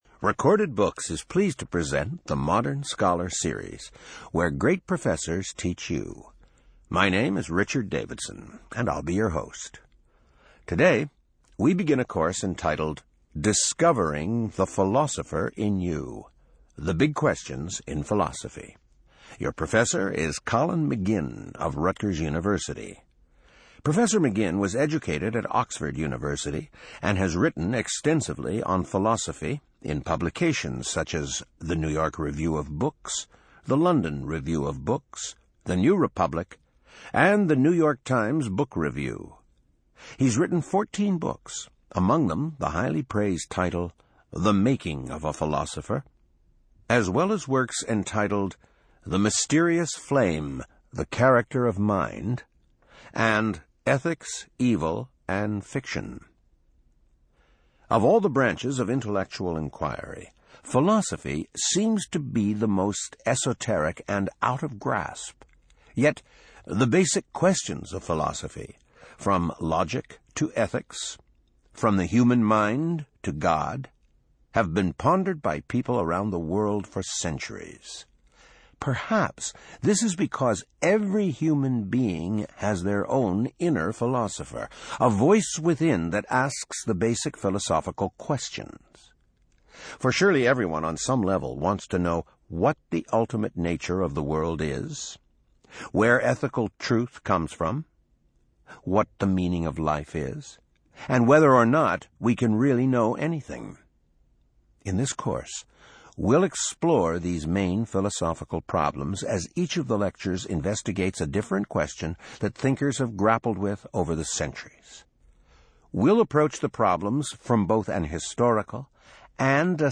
In this lecture, Professor Colin McGinn deciphers what we mean when we say we 'know' something to be true.